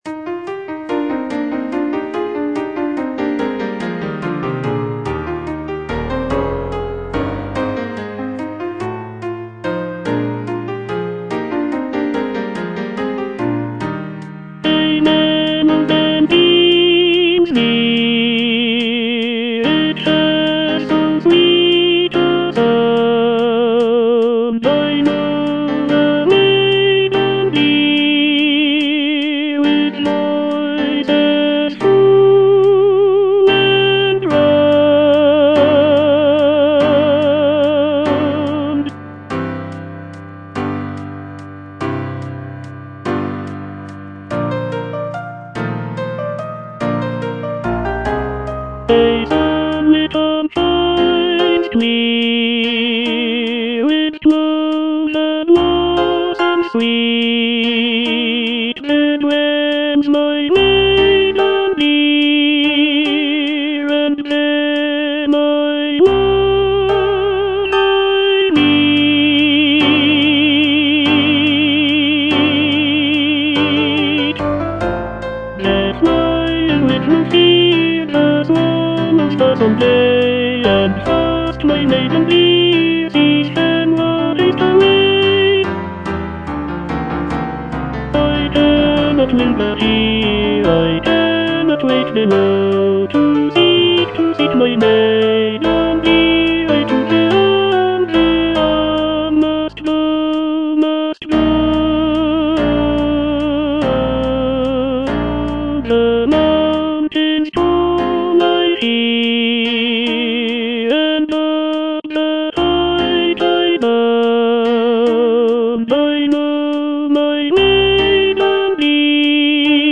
E. ELGAR - FROM THE BAVARIAN HIGHLANDS On the alm (tenor I) (Voice with metronome) Ads stop: auto-stop